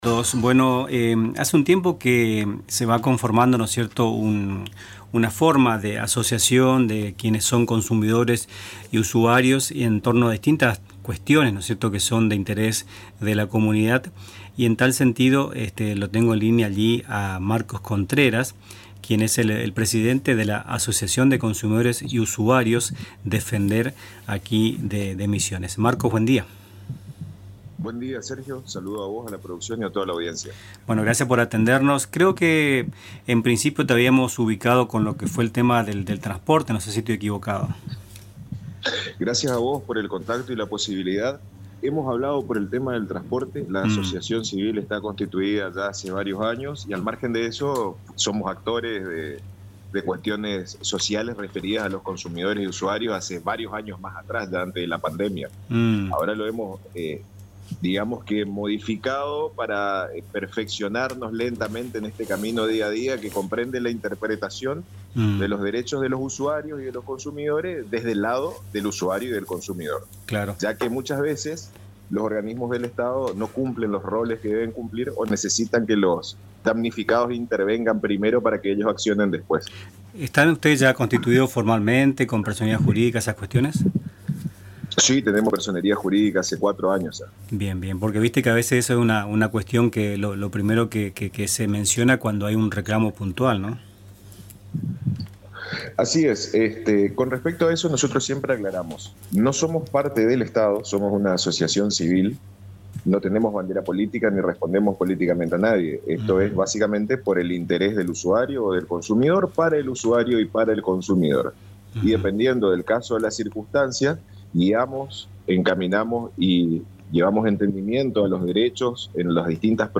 Escuchá el audio de la entrevista en Radio Tupambaé